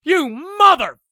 gun_jam_1.ogg